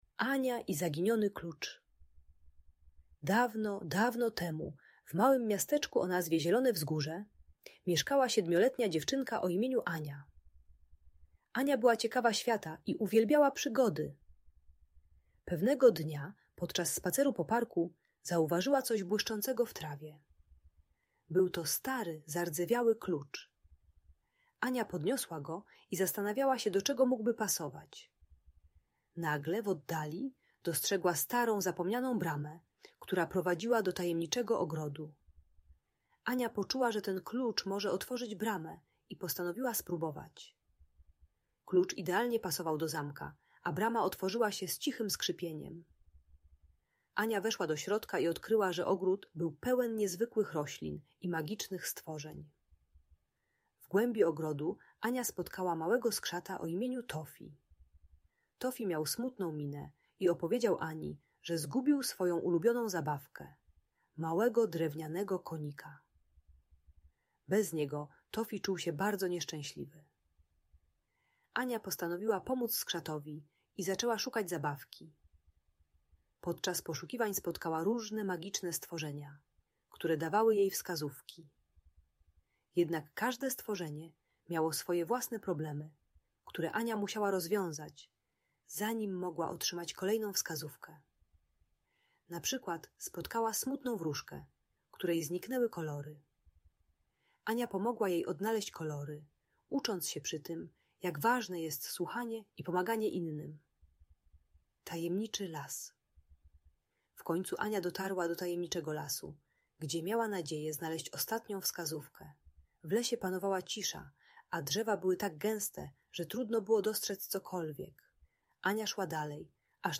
Ania i Zaginiony Klucz - Bunt i wybuchy złości | Audiobajka